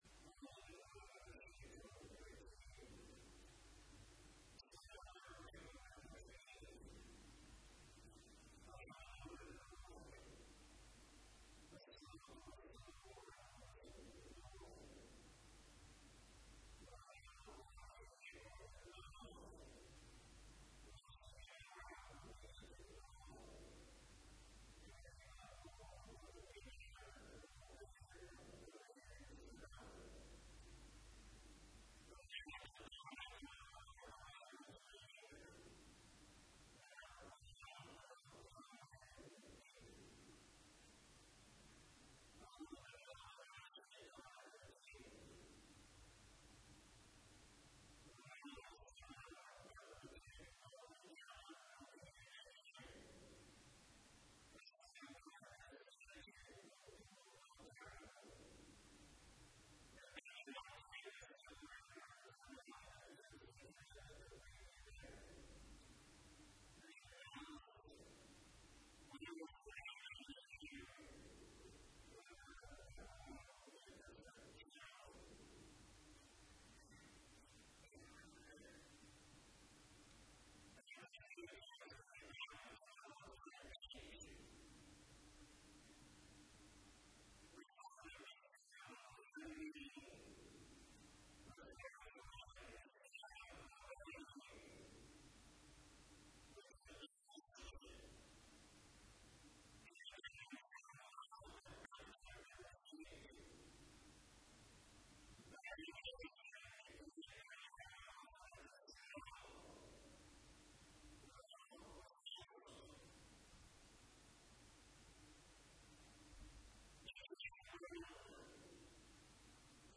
درس دهم توضیح آیات
استاد بزرگوار در این مجلس ذیل آیات ۴۳ و ۴۴ سوره نحل پیرامون مساله نبی و رسول و مساله وحی به ایراد سخن پرداختند.